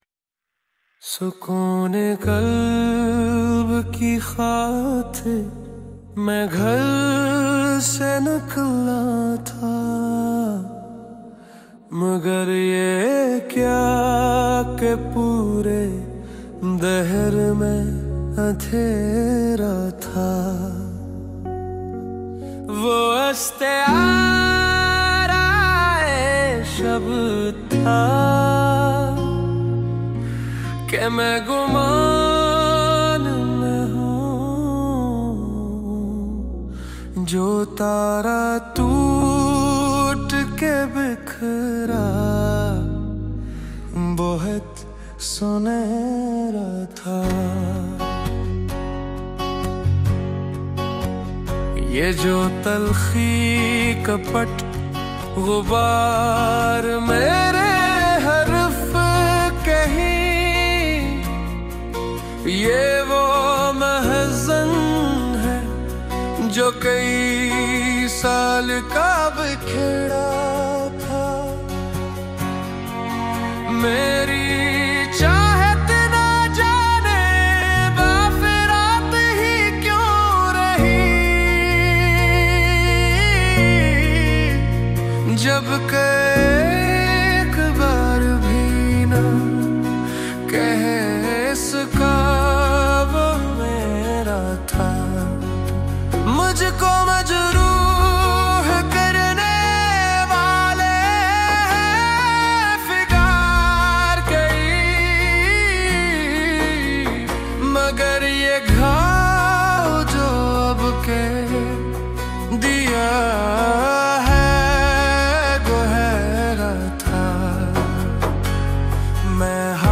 •  Lyrics born from the heart, melodies crafted with AI.